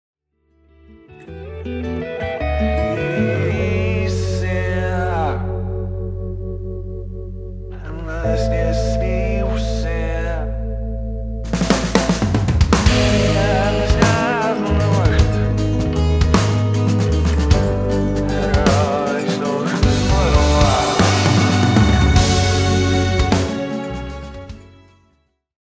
Genere: progressive death metal
Rovesciato
Incomprensibile